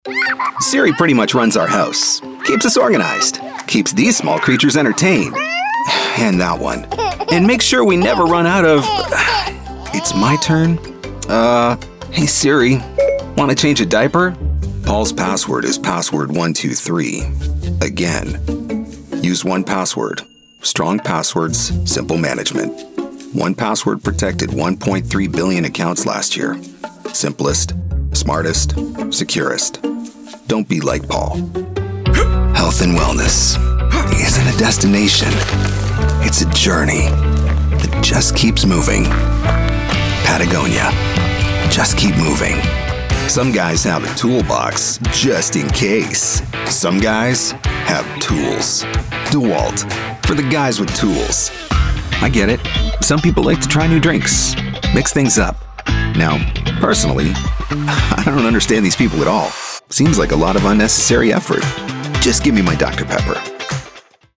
Male Talent
COMMERCIAL DEMO